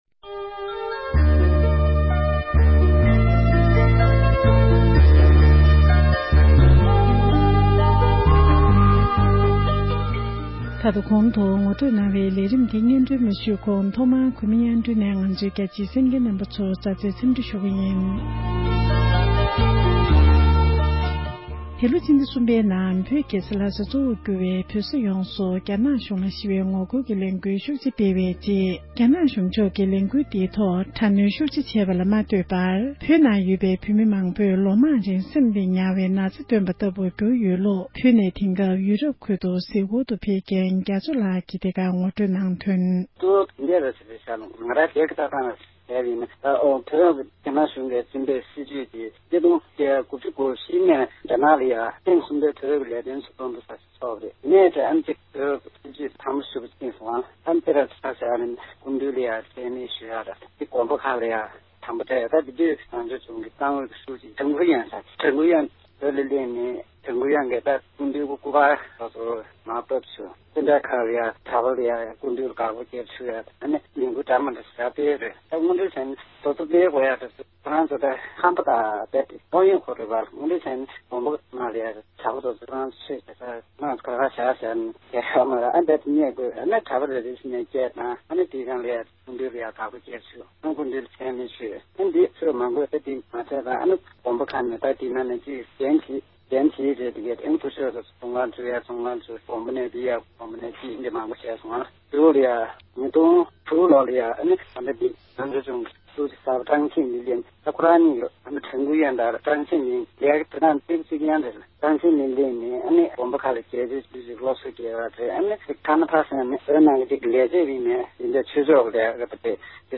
འབྲེལ་ཡོད་མི་སྣར་གནས་འདྲི་ཞུས